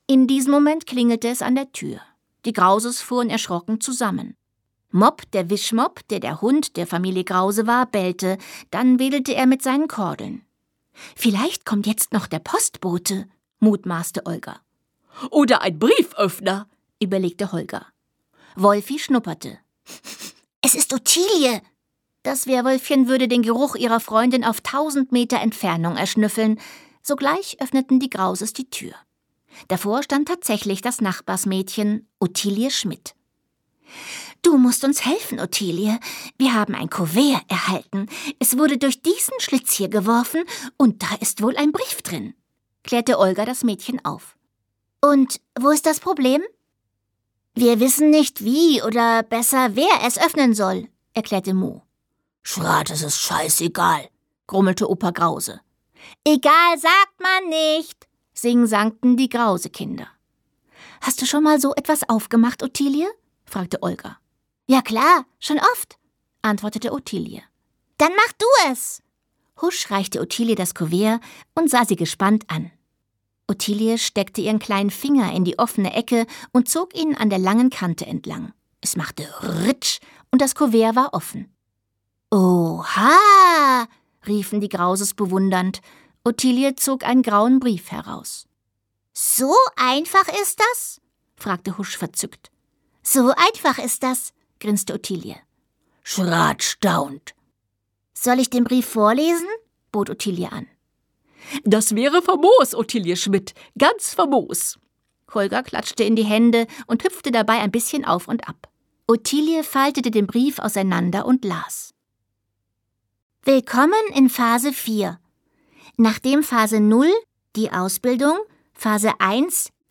Sprecherin: Sabine Bohlmann
gekürzte Autorenlesung
Das Ganze wieder gesprochen von der Autorin herself, wirklich klasse, wie sie allen so ihre eigenen Stimmen verleiht.